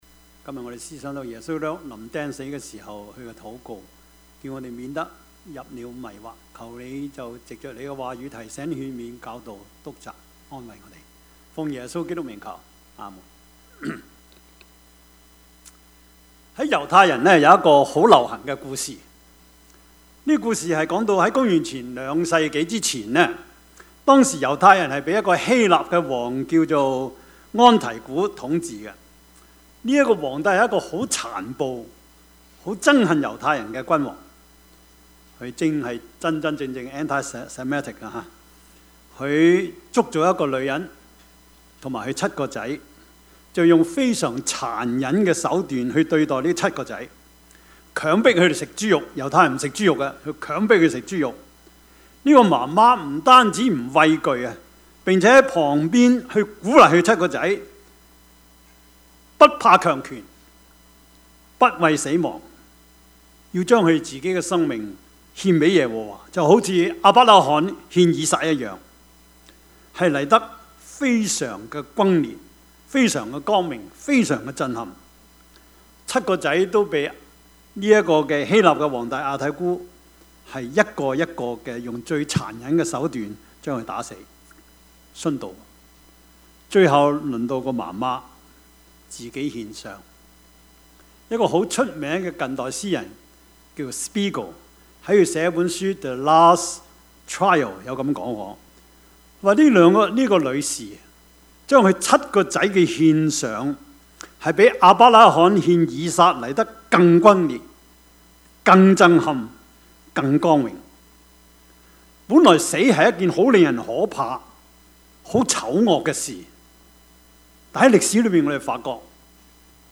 Service Type: 主日崇拜
Topics: 主日證道 « 摩西與以利亞—走得有型 金齡歲月 »